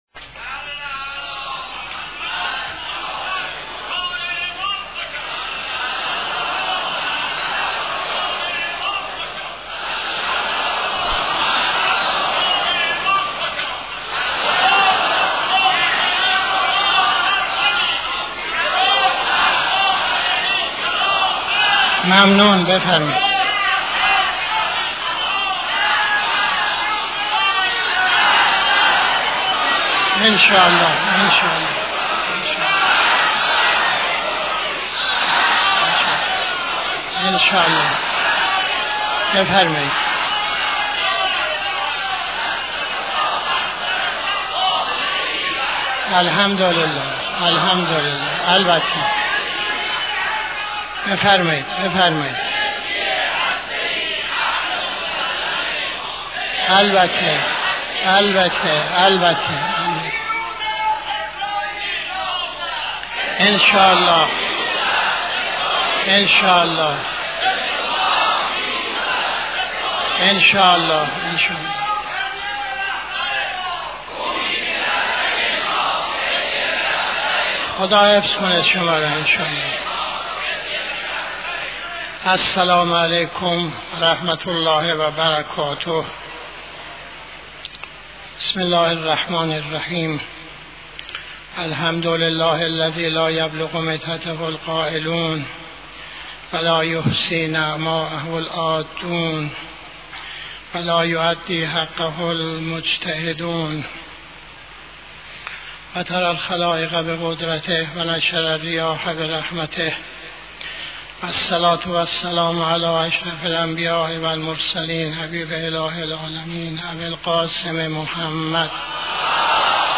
خطبه اول نماز جمعه 13-05-85